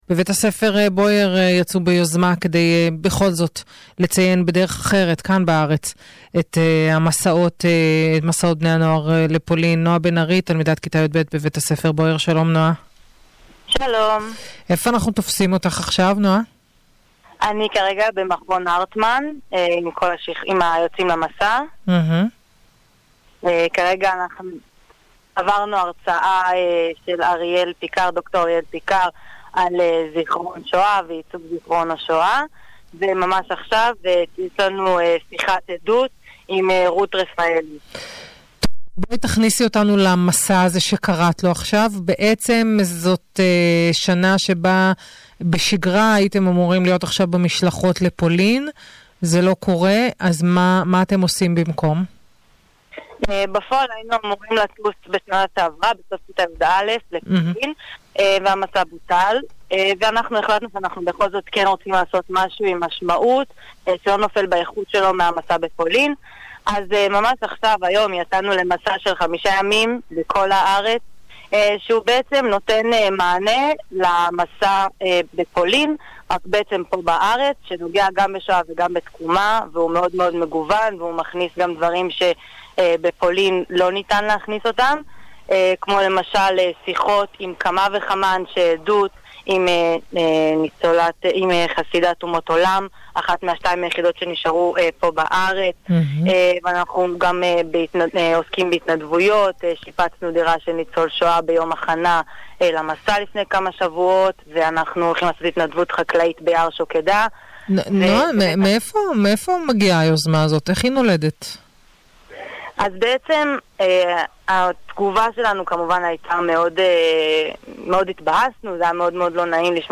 ראיון ברדיו קול ירושלים על המסע האלטרנטיבי לפולין של תלמידי תיכון בויאר
radioKol.mp3